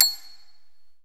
CELESTE C#6.wav